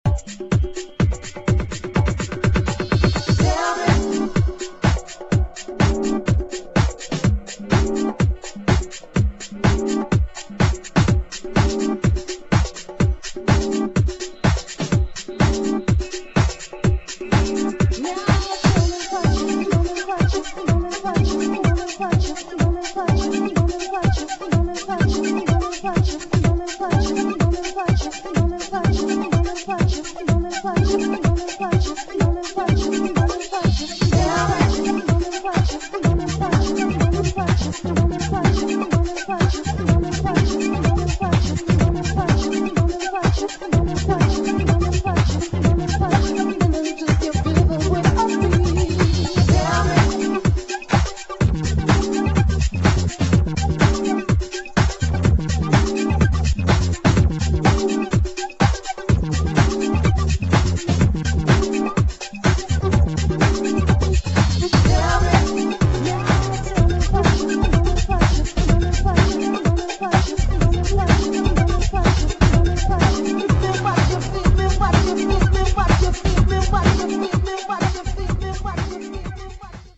[ JAZZ / FUNK / SOUL / HOUSE ]